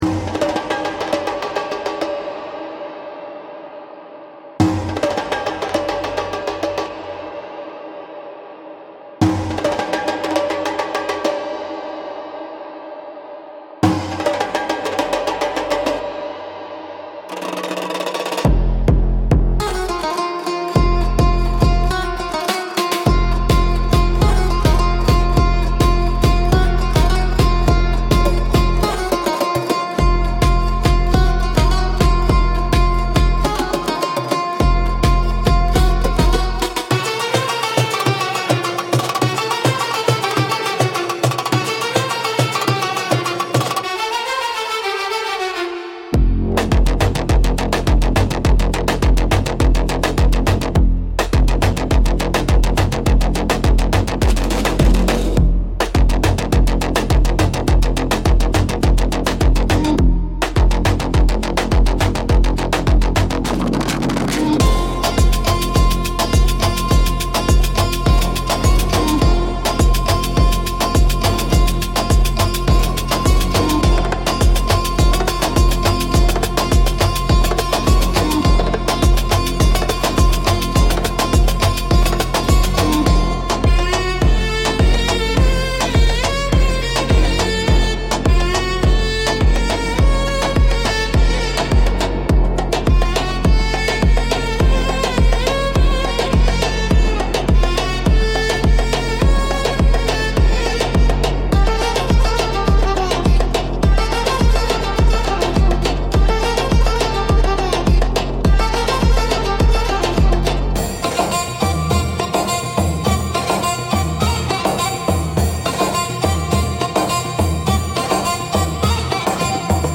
Instrumental - Blood Moon Mirage